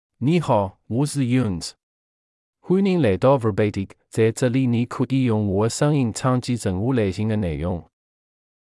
MaleChinese (Wu, Simplified)
Yunzhe — Male Chinese AI voice
Yunzhe is a male AI voice for Chinese (Wu, Simplified).
Voice sample
Yunzhe delivers clear pronunciation with authentic Wu, Simplified Chinese intonation, making your content sound professionally produced.